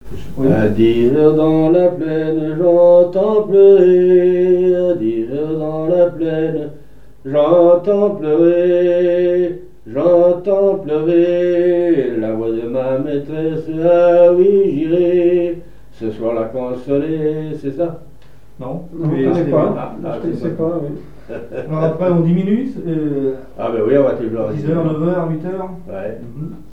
Fonction d'après l'analyste gestuel : à marcher
Genre énumérative
La pêche au thon et des chansons maritimes
Pièce musicale inédite